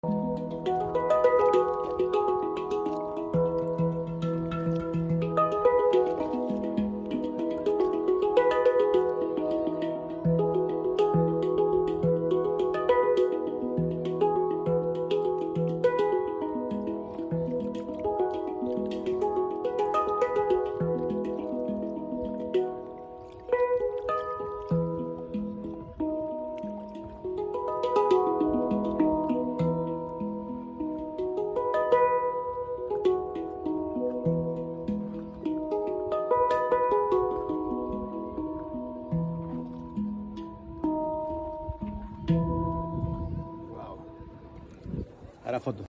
Yoga and handpan at sunset.